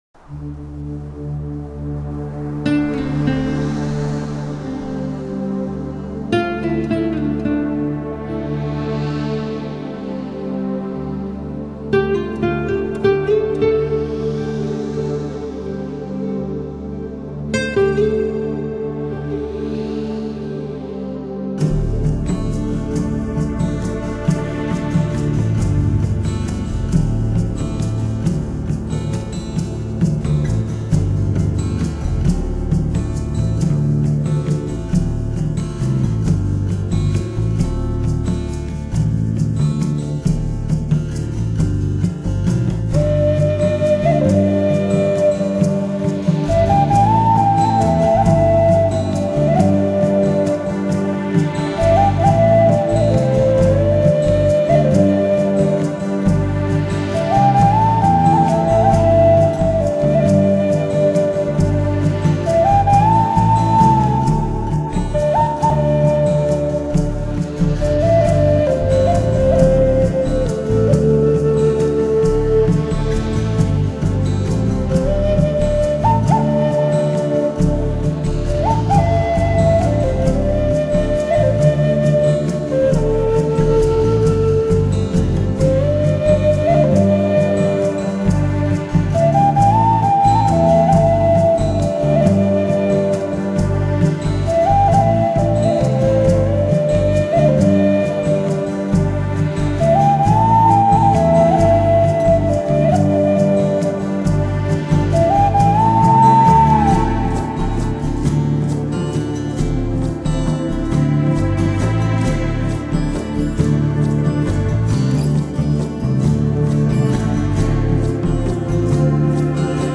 因此这张专辑有着浓厚的非洲风格。